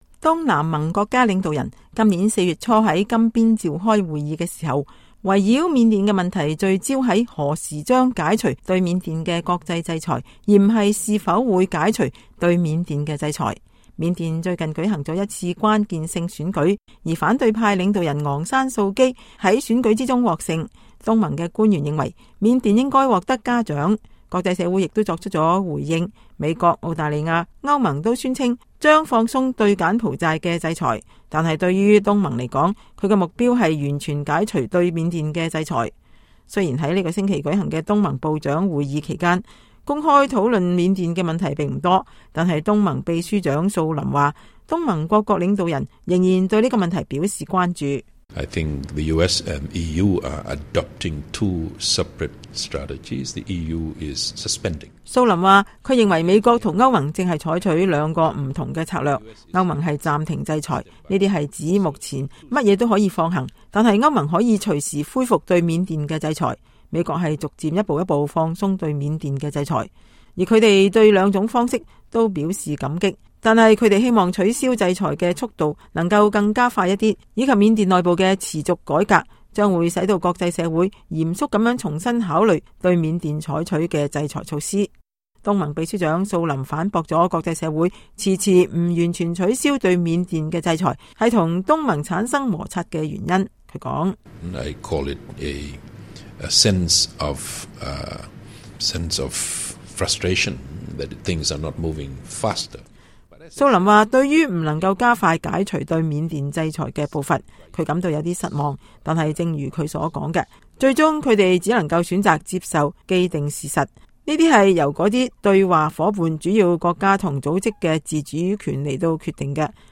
在接受VOA的採訪中，東盟秘書長素林說， 東盟成員國應在鼓勵緬甸的改革上得到應有的稱讚。他同時也對沒有完全解除對緬甸的國際制裁感到失望。